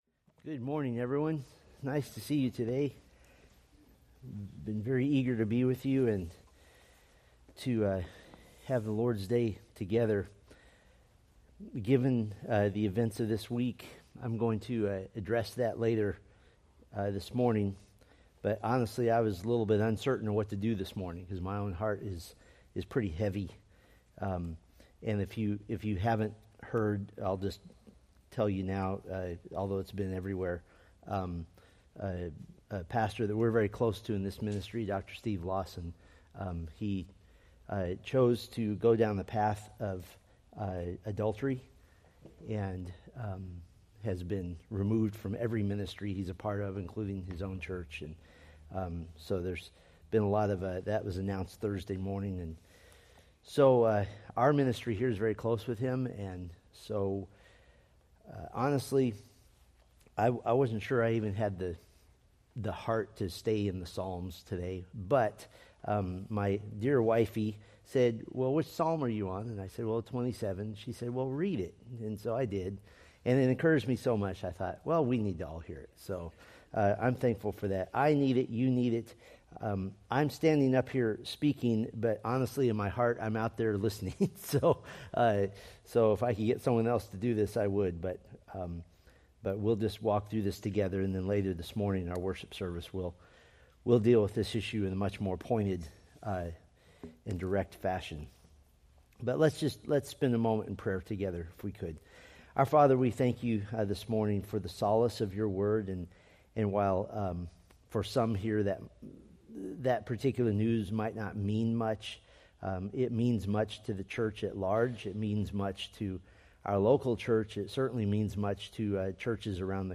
Date: Sep 22, 2024 Series: Psalms Grouping: Sunday School (Adult) More: Download MP3